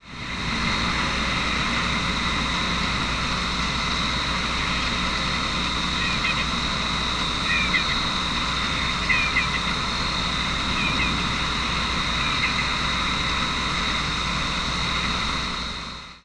Lesser Nighthawk Chordeiles acutipennis
Flight call description A low chuck or double chuck is occasionally uttered in flight on the wintering grounds, and a variable nasal "waay-da-da-da" is given in flight on the breeding grounds.
Nocturnal calling sequences:
"Waay-da-da-da" call from bird in flight on the breeding grounds.Killdeer calling in the background.